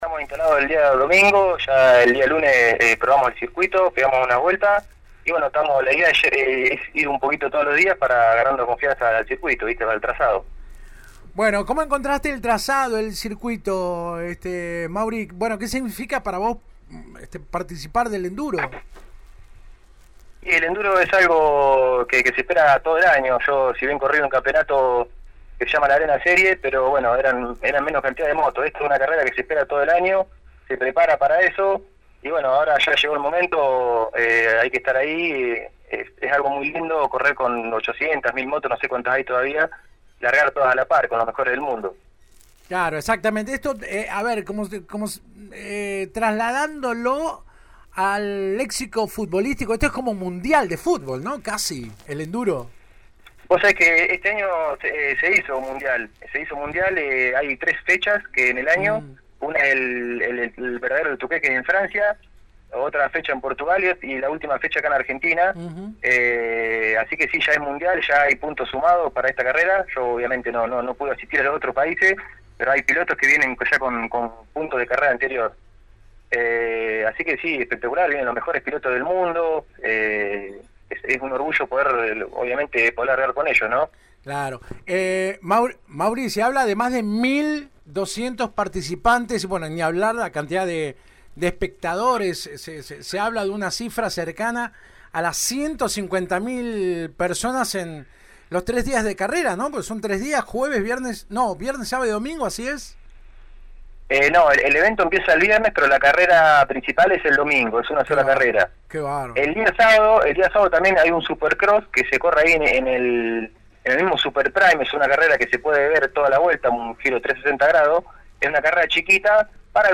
habló este miércoles en la 91.5. «Contento de estar de nuevo en esta competencia luego de dos años de ausencia por la pandemia.